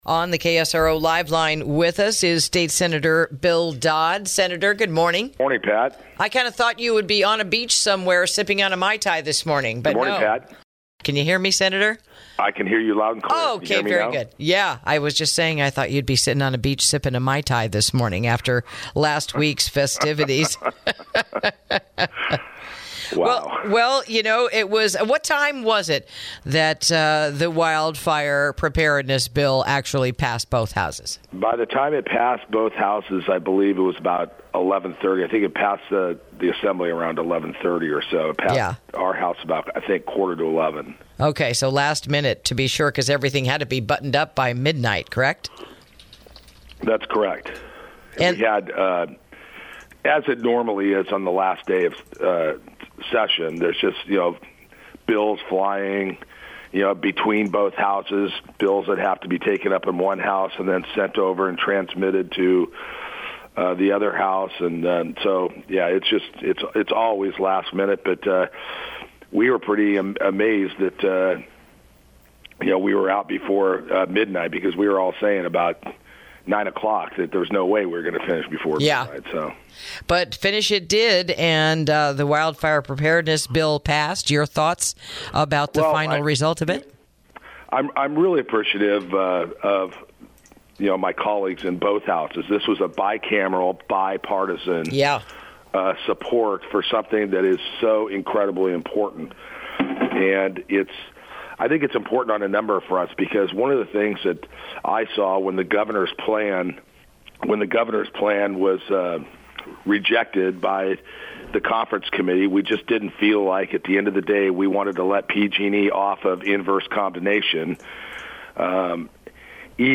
INTERVIEW: The Pros and Cons of the Completed Wildfire Preparedness Bill